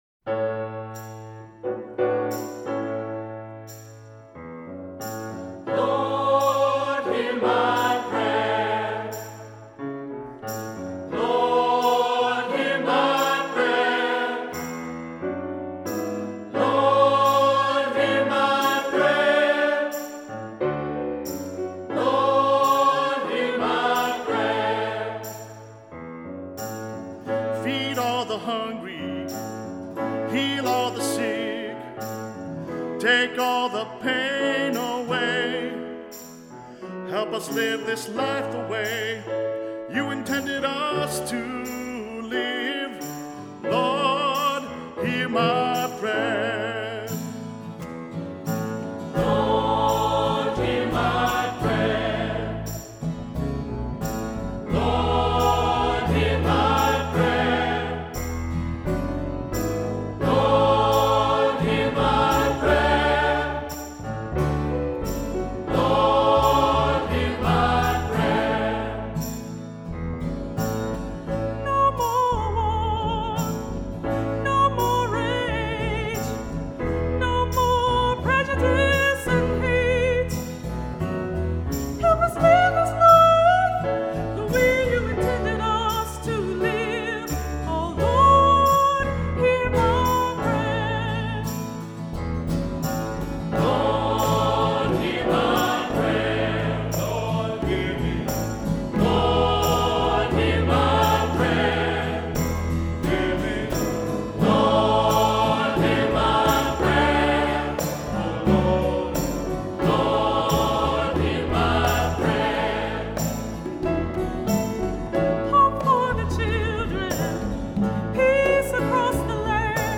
Voicing: SATB; Cantor; Solo; Assembly